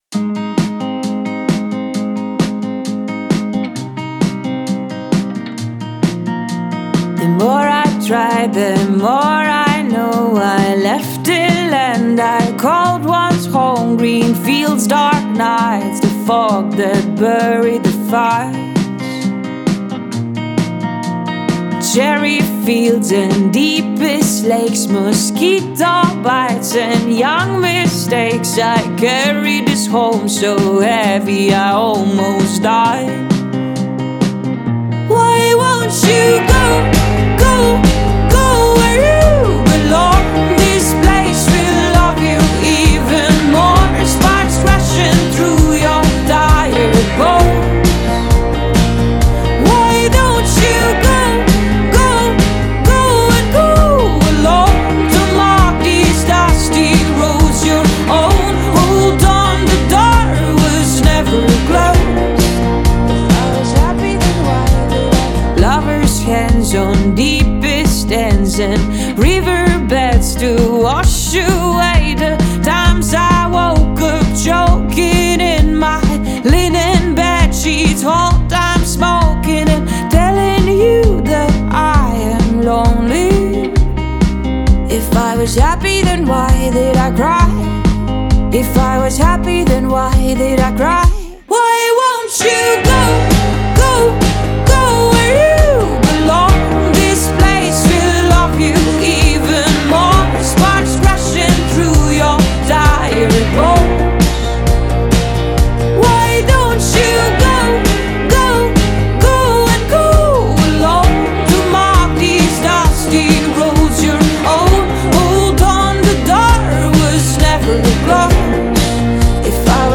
indie pop
the song layers slide guitar